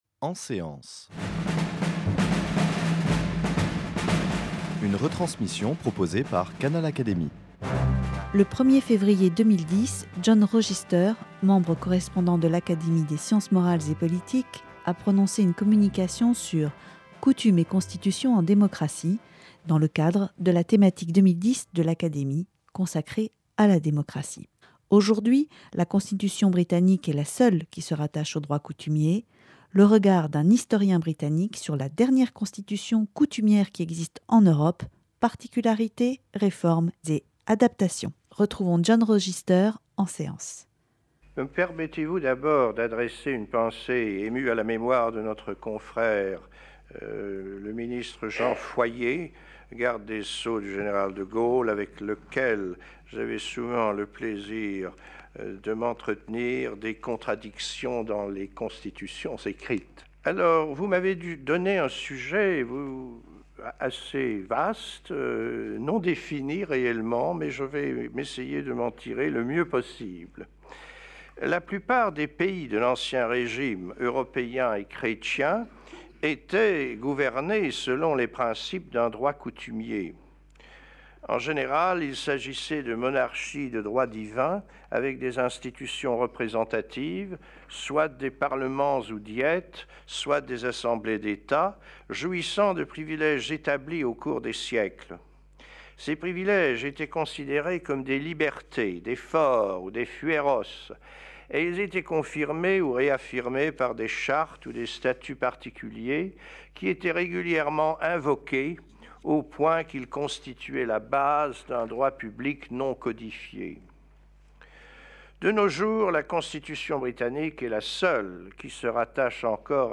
En séance, Académie des sciences morales et politiques